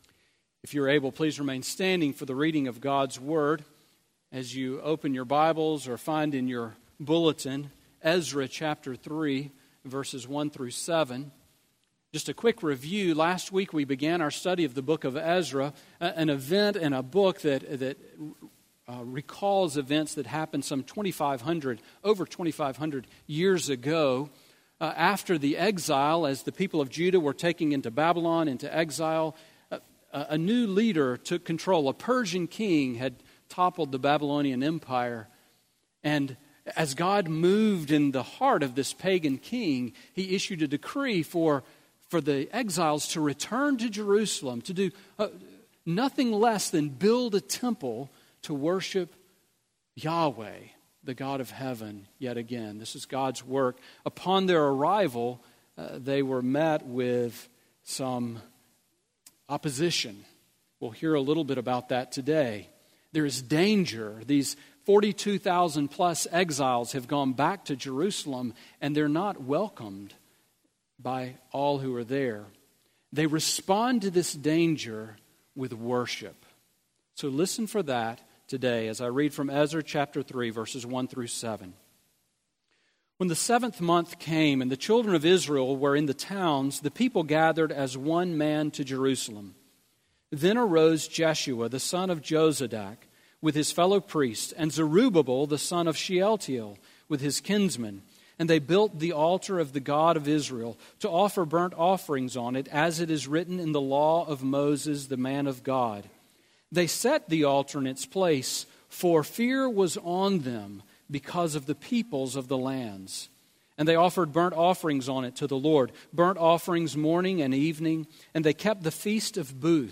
Sermon on Ezra 3:1-7 from June 11